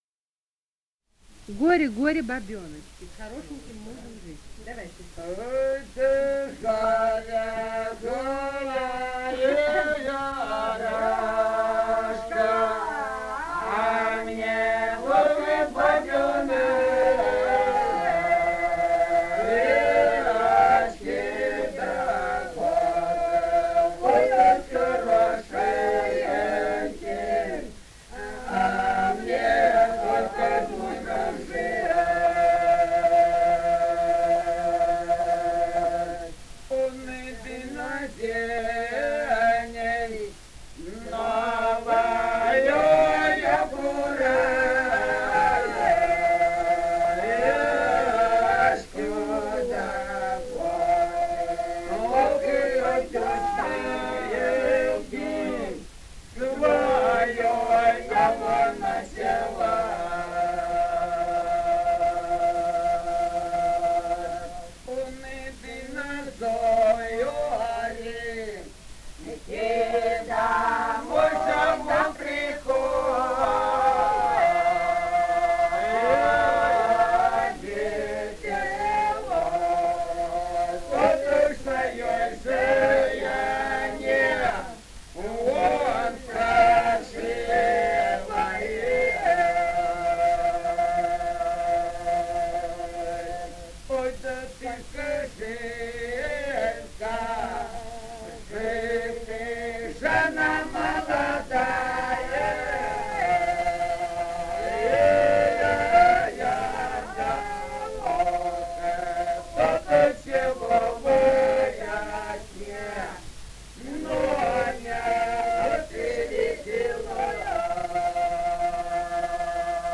полевые материалы
Ростовская область, ст. Вёшенская, 1966 г. И0940-05